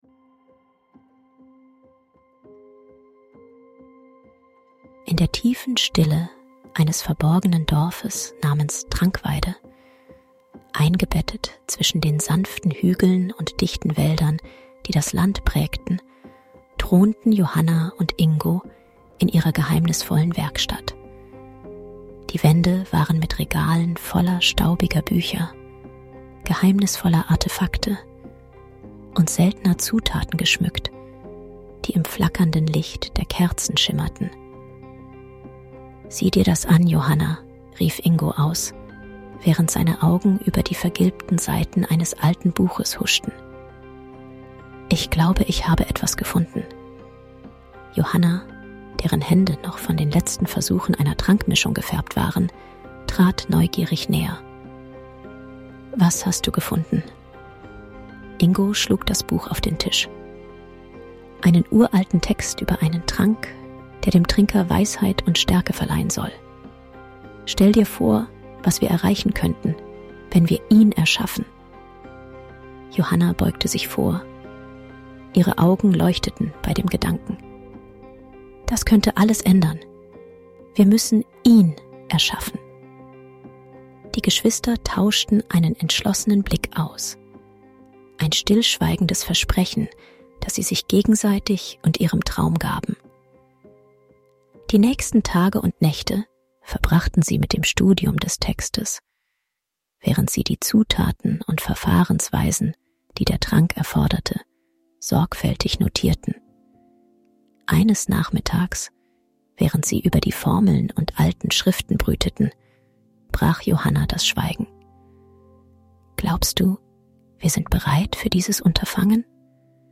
Lauscht der spannenden Erzählung und lasst euch von der Welt der alten Alchemie verzaubern, wo jeder Trank eine neue Tür zu unbekannten Welten öffnet.